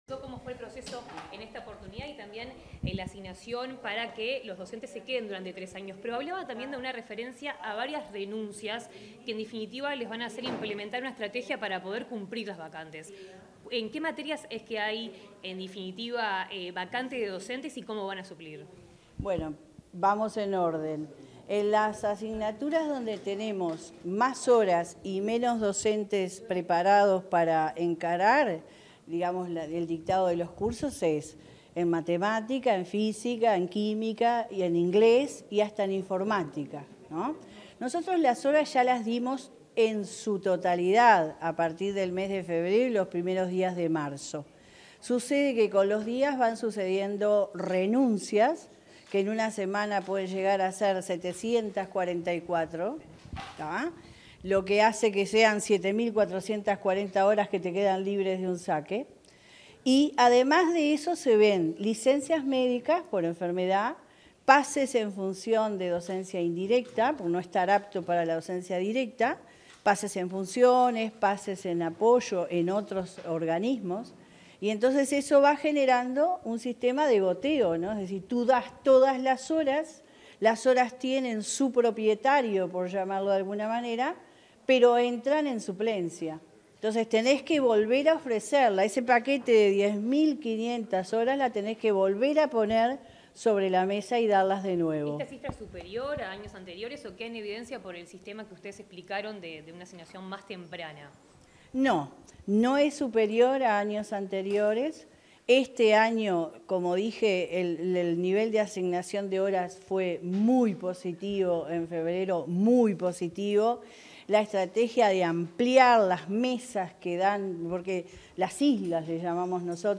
Declaraciones de la directora general de Educación Secundaria, Jenifer Cherro
Declaraciones de la directora general de Educación Secundaria, Jenifer Cherro 16/04/2024 Compartir Facebook X Copiar enlace WhatsApp LinkedIn Tras la presentación de los datos sobre el inicio de cursos, este 16 de abril, la directora general de Educación Secundaria (DGES), Jenifer Cherro, realizó declaraciones a la prensa.